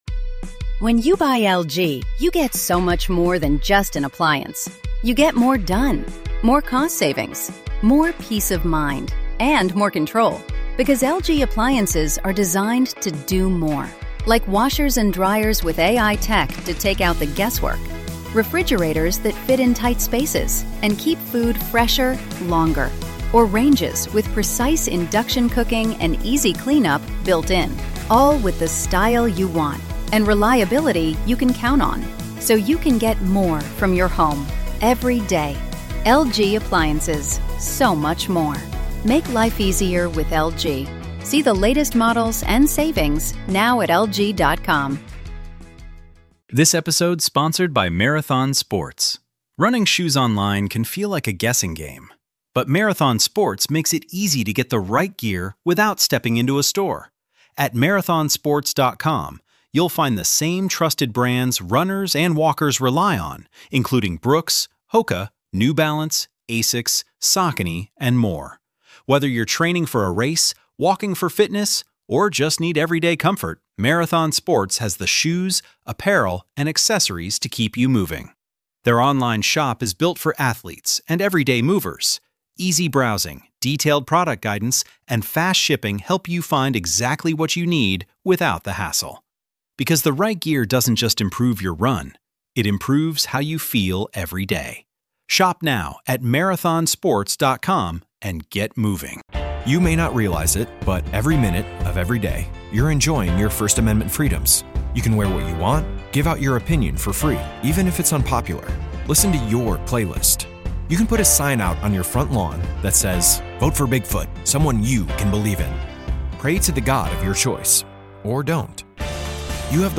Guest Info/Bio: This week I had the chance to talk one of my favorite scholars, Dr. Dan O. McClellan in part 2 of 2 episodes.